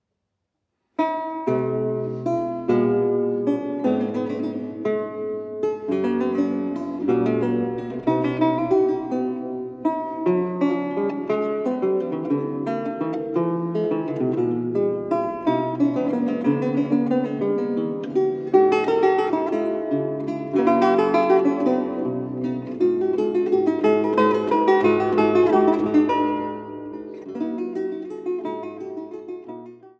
Laute
Bearbeitung für Laute